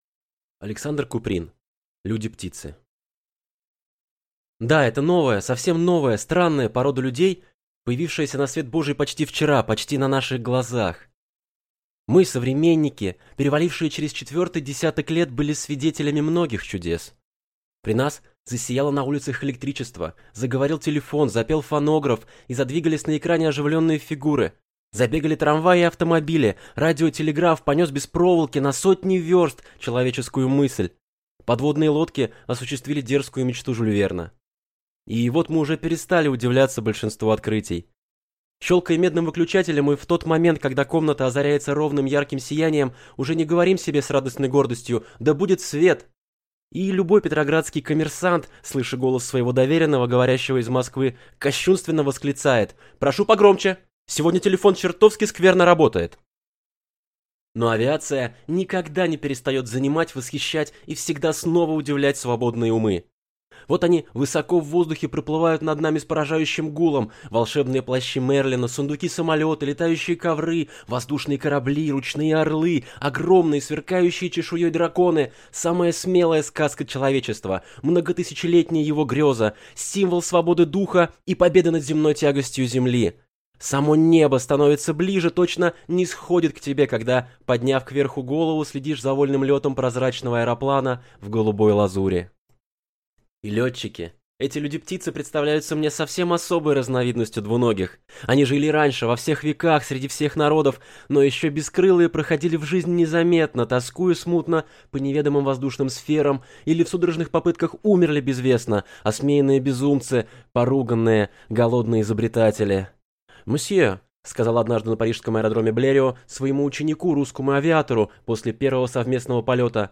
Аудиокнига Люди-птицы | Библиотека аудиокниг